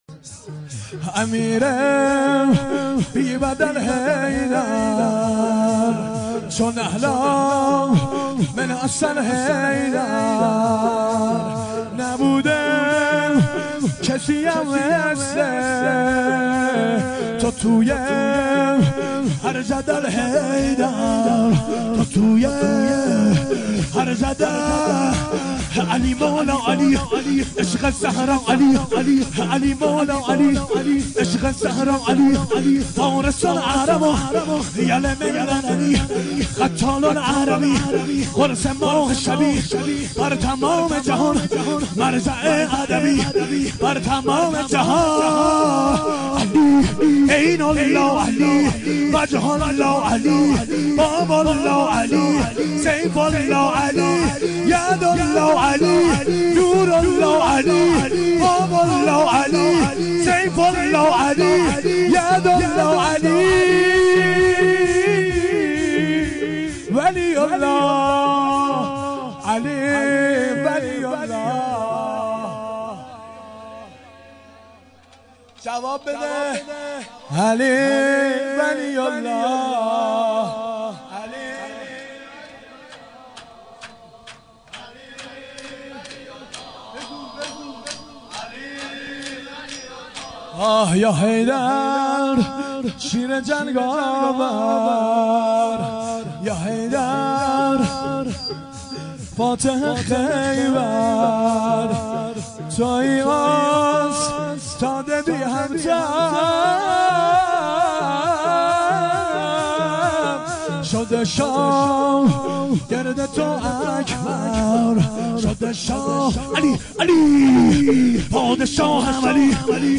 شور
شب پنجم محرم ۱۴۴۱